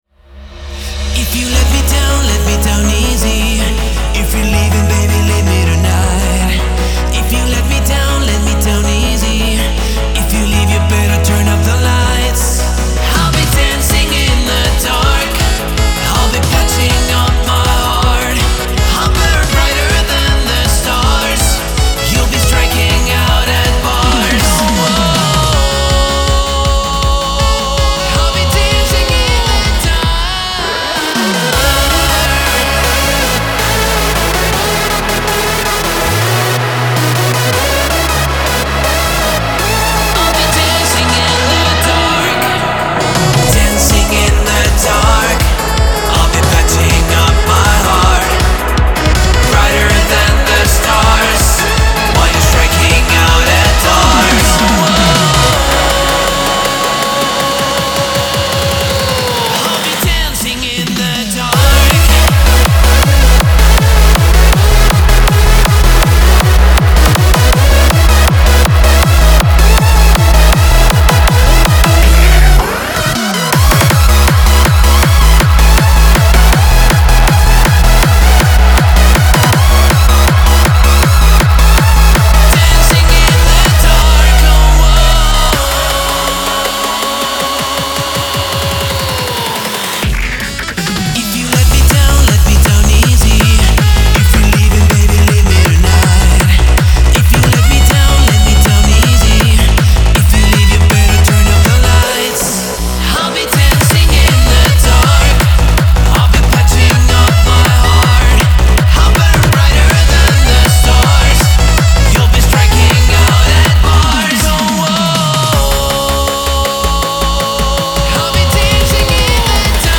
дуэт
диско